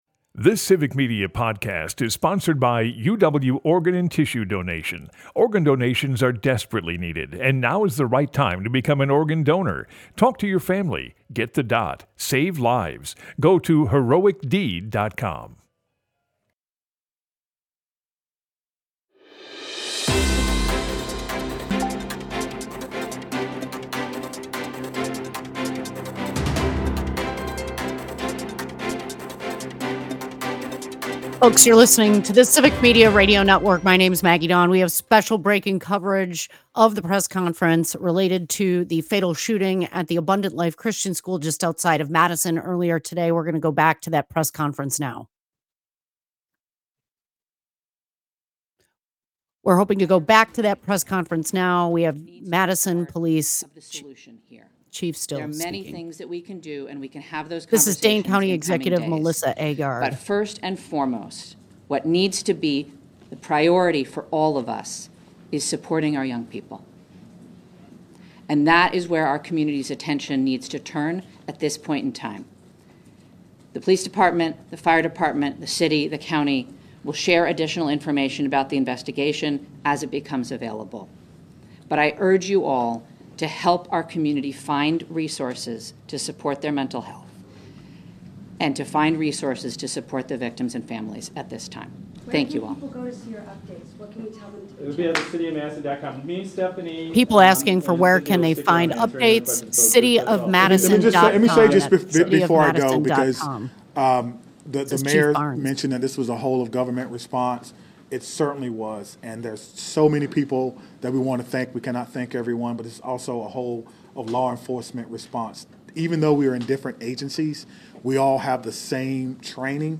We also listen in to the live press conference with Madison Police Chief Barnes and other local officials.